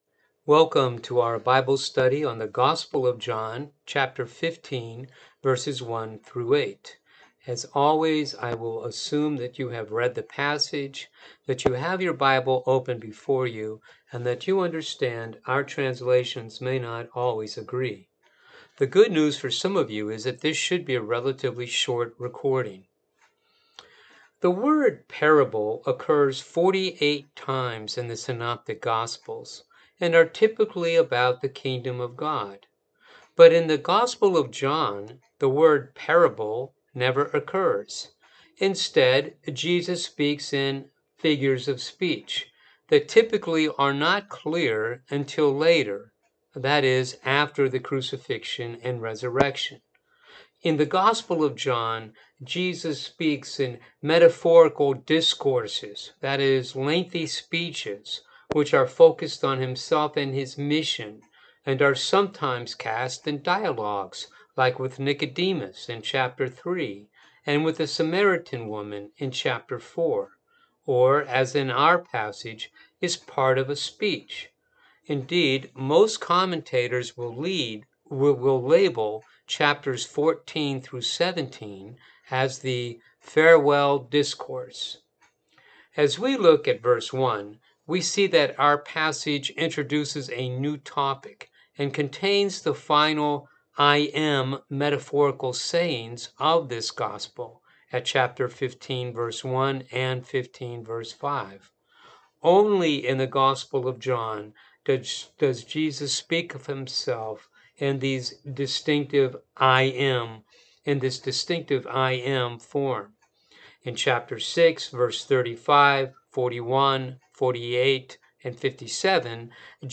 Bible Study Online Click to Hear Sermon